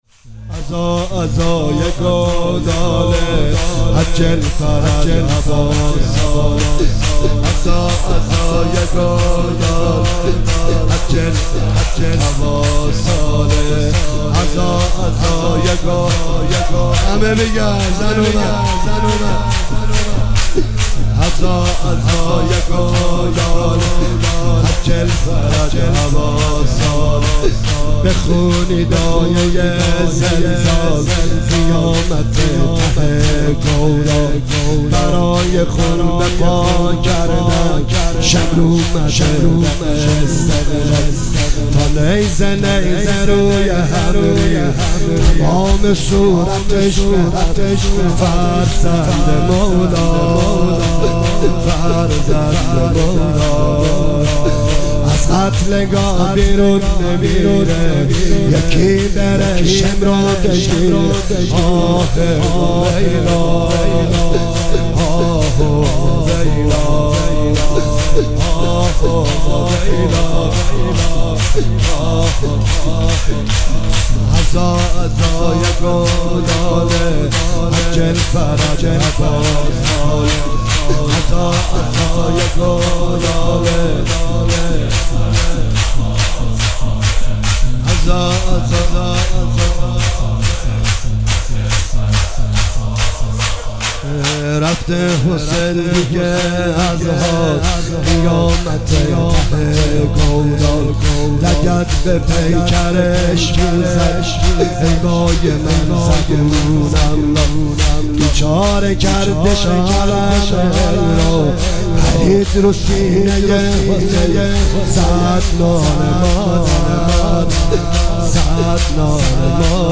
روز عاشورا 1404
مداحی شور لطمه زنی
روز عاشورا شب دهم محرم